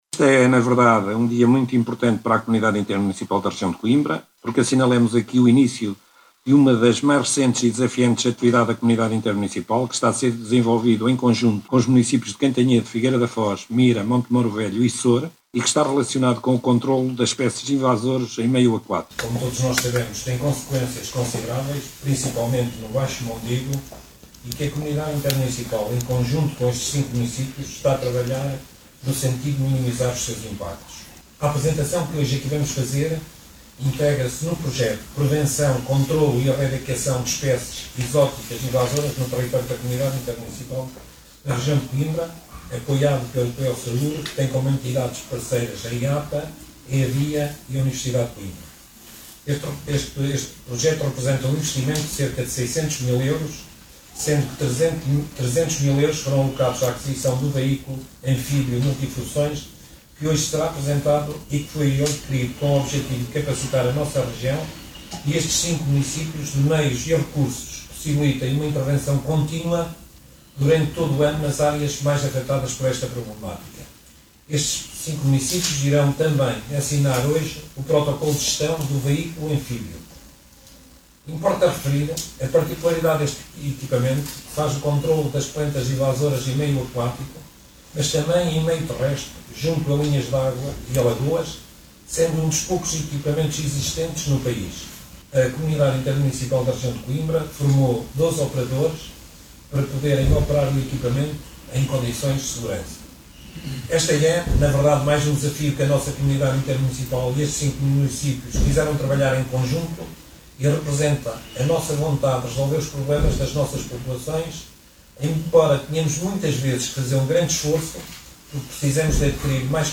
O projecto de Gestão de Plantas Invasoras Aquáticas no Território da CIM Região de Coimbra inclui os municípios de Mira, Figueira da Foz, Montemor-o-Velho, Soure e Cantanhede, e foi apresentado hoje no Centro de Alto Rendimento de Montemor-o-Velho.
A sua aquisição visa “capacitar a região e estes cinco municípios com meios e recursos para uma intervenção contínua durante todo o ano nas áreas mais afetadas por esta problemática”, frisou José Carlos Alexandrino. Oiça aqui as declarações do presidente da CIM RC: